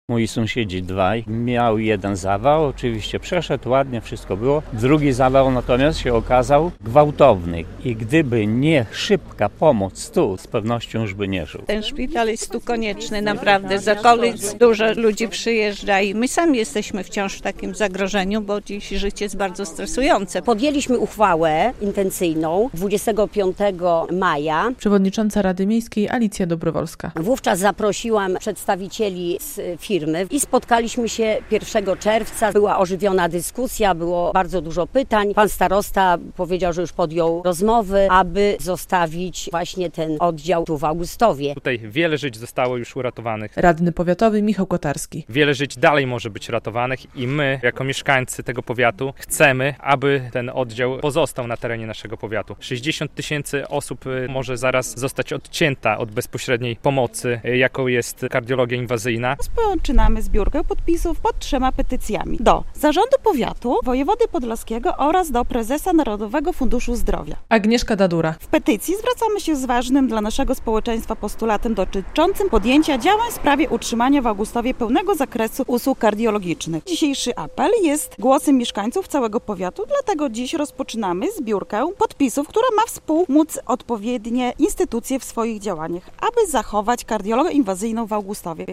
relacja
Podczas piątkowej (3.06) konferencji augustowianie zapowiedzieli zbiórkę podpisów pod petycją w tej sprawie.